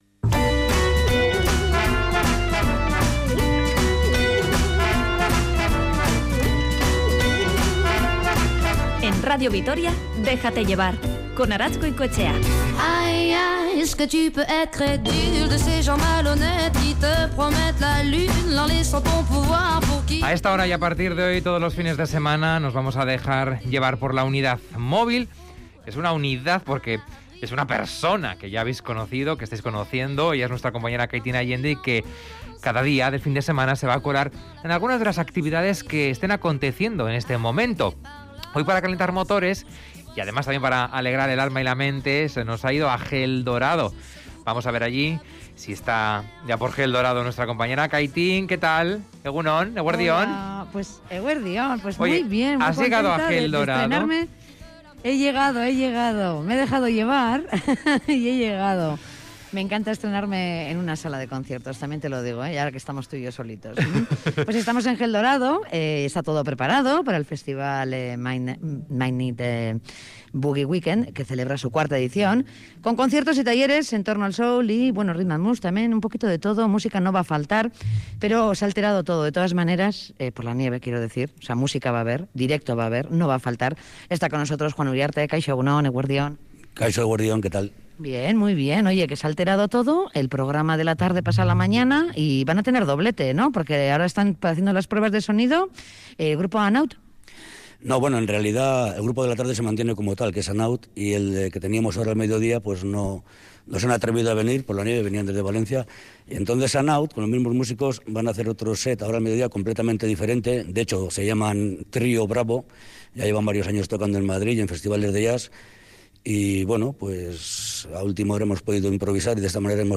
Sesión vermút en Hell Dorado con música en directo desde Madrid a Vitoria-Gasteiz
Audio: Nos hemos colado en las pruebas de sonido de Hell Dorado con la banda Trío Bravo. Déjate Llevar ha estado allí en directo.